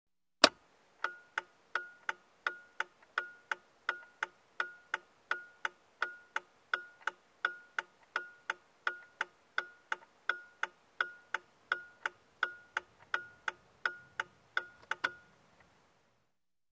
Звуки поворотников
На этой странице собраны различные звуки поворотников автомобилей: от классических щелчков реле до современных электронных сигналов.